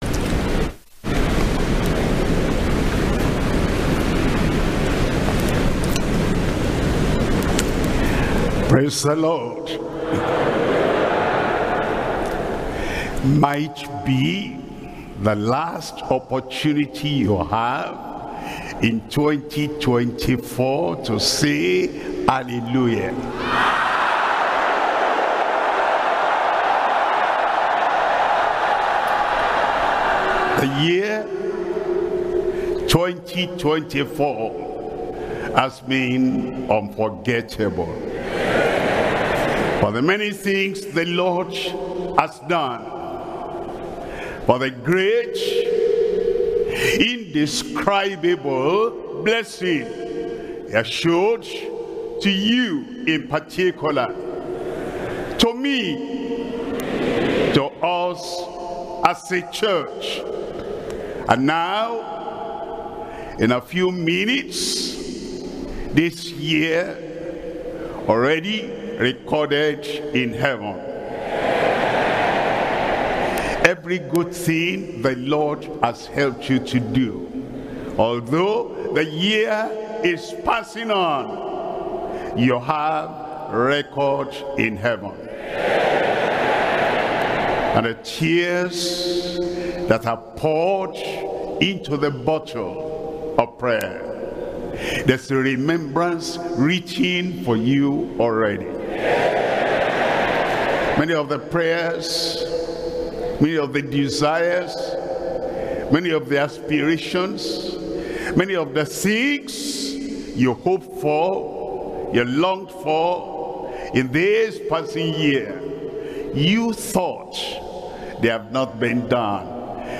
SERMONS – Deeper Christian Life Ministry Australia
2024 Watchnight Service